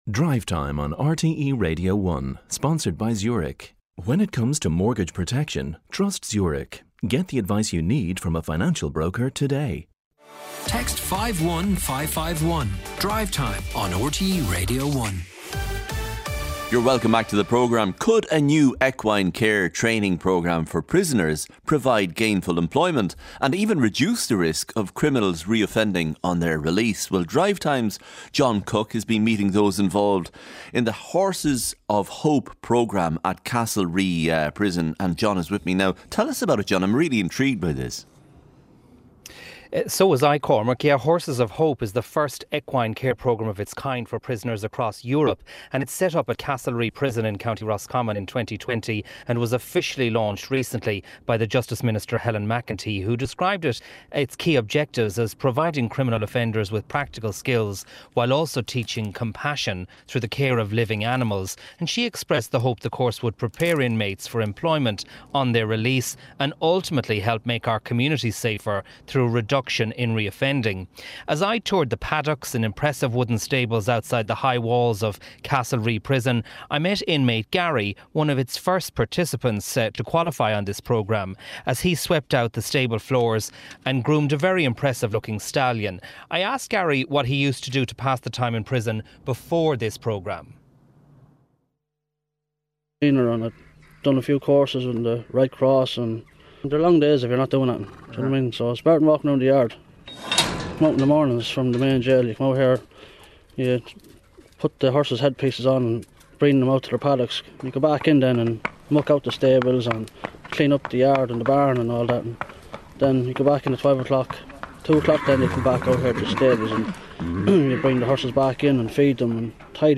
7:35am Sports News - 03.06.2022